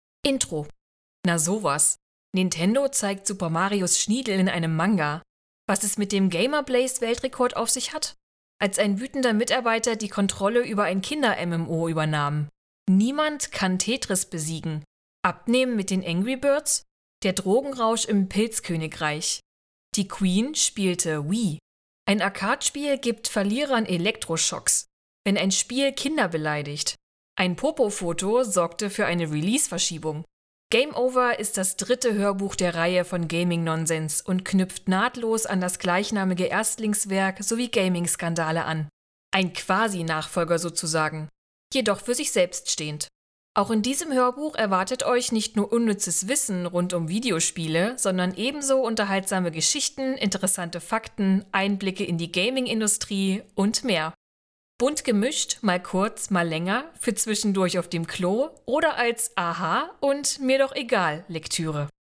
• Sprecherin